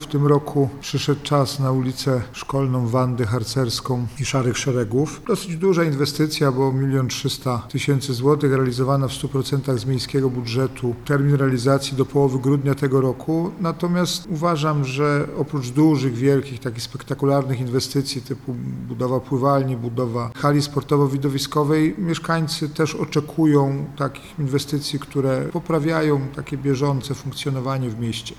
– W czasie pięciu lat wyremontujemy wiele chodników – powiedział prezydent Jacek Milewski: